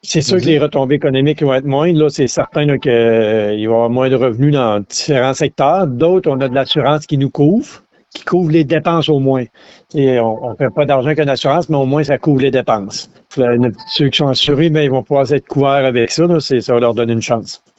Étant un agriculteur, le préfet de la MRC de Bécancour, Mario Lyonnais, est revenu sur les dernières semaines.